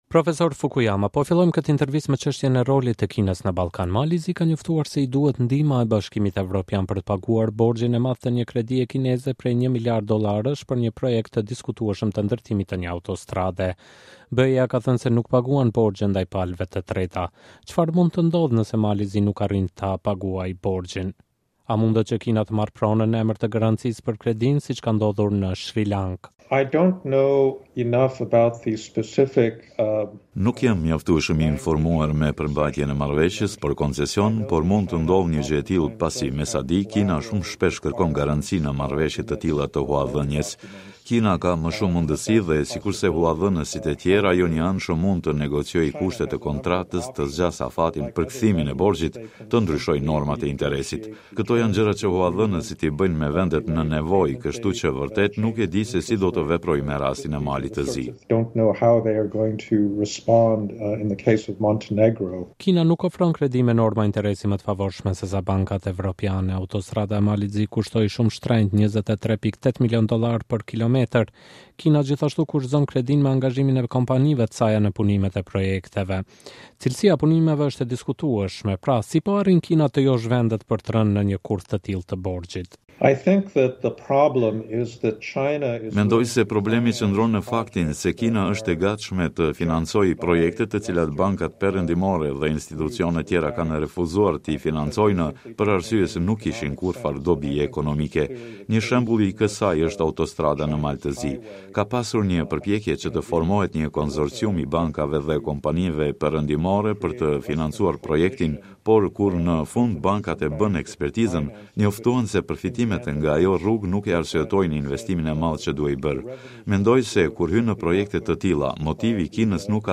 Fukuyama: Kina nuk ka vetëm interesa ekonomike në Ballkan Përmes projekteve në Ballkan, Kina nuk ka vetëm motive ekonomike, por edhe të politikës së jashtme dhe qëllime strategjike, thotë në një intervistë për Radion Evropa e Lirë, Francis Fukuyama, profesor në Universitetin e Stanfordit dhe...